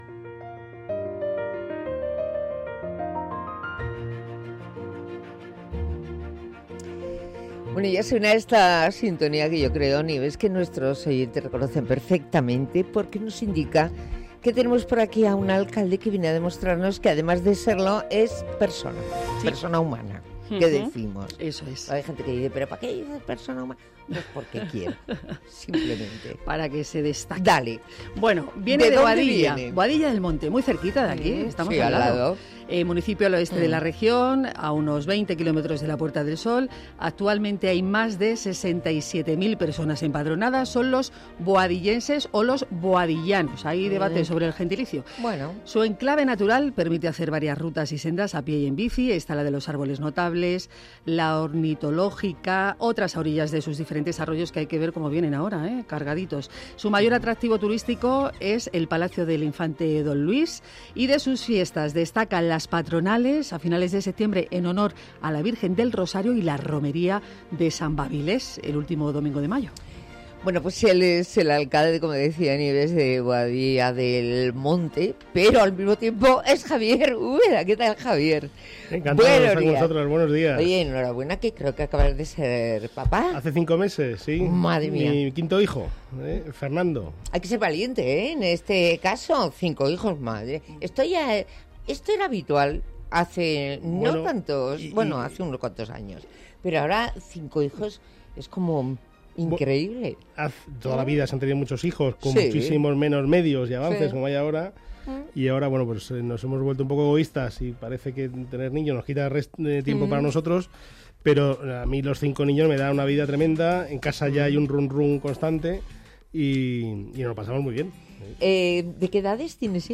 Javier Úbeda, regidor de Boadilla del Monte ha sido el invitado esta semana en la sección Los alcaldes también son humanos de Onda Madrid.